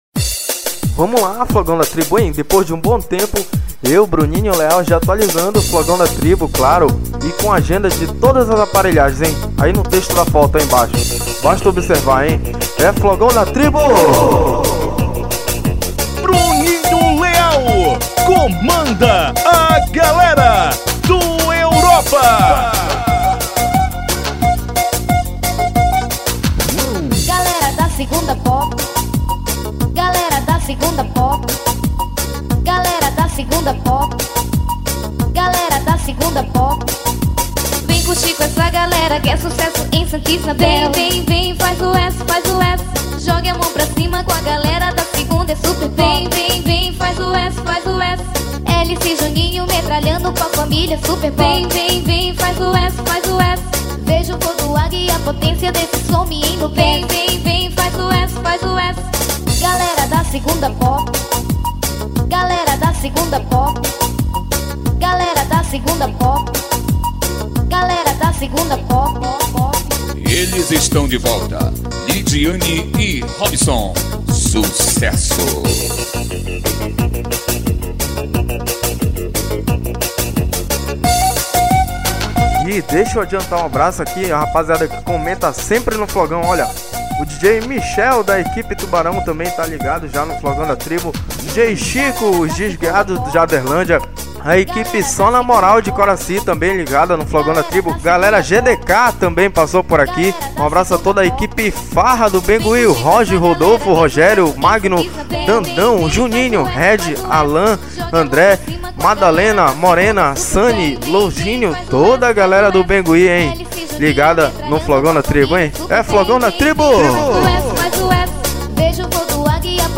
Composição: Pop.